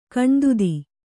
♪ kaṇdudi